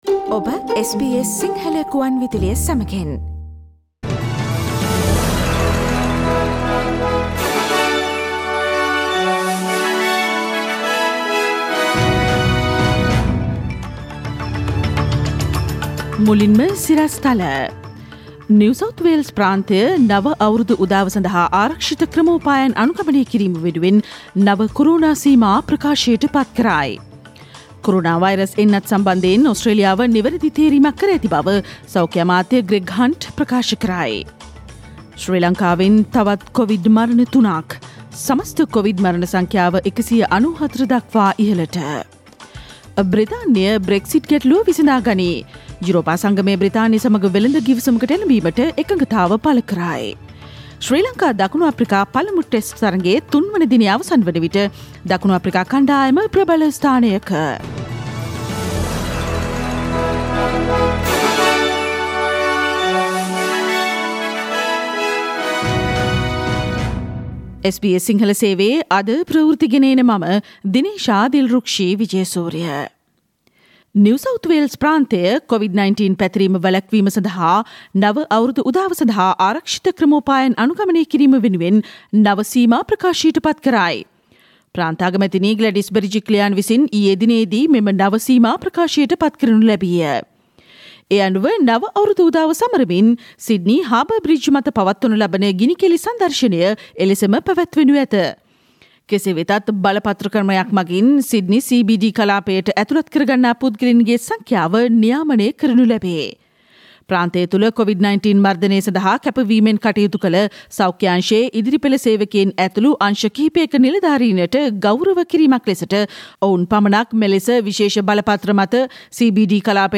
Daily News bulletin of SBS Sinhala Service: Tuesday 29 December 2020
Today’s news bulletin of SBS Sinhala radio – Tuesday 29 December 2020.